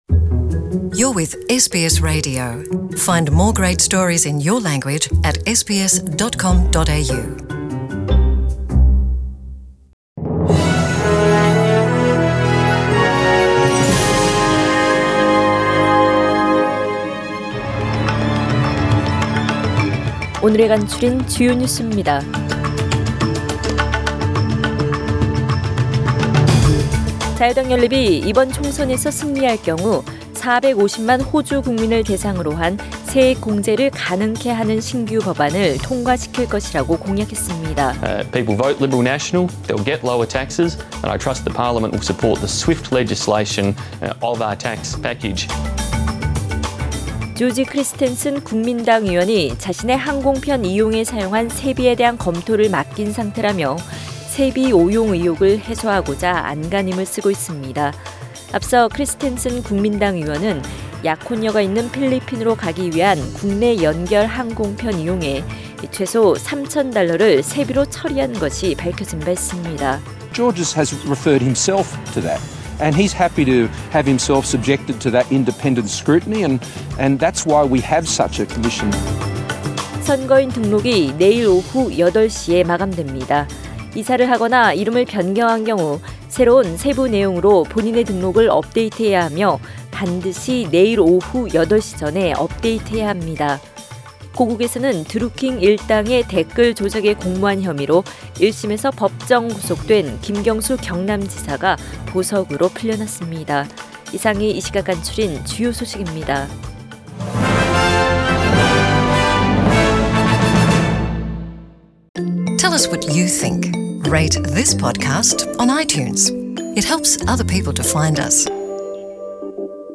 SBS 한국어 뉴스 간추린 주요 소식 – 4월 17일 수요일